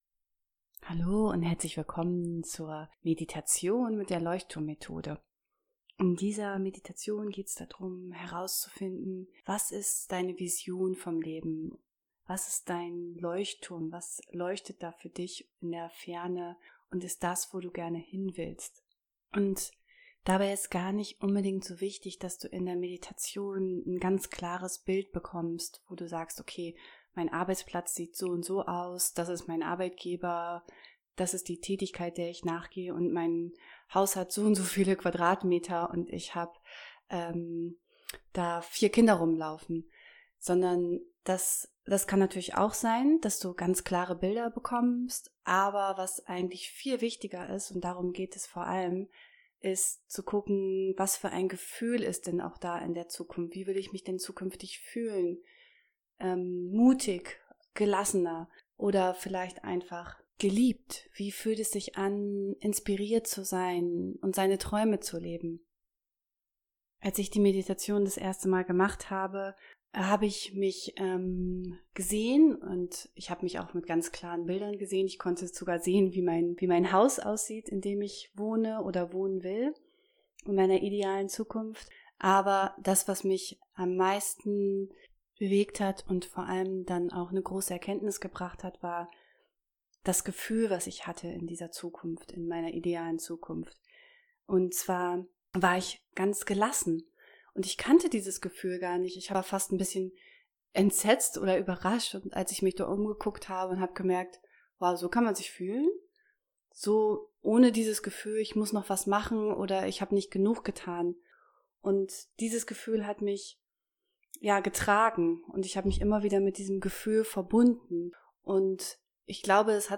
Leuchtturm-meditation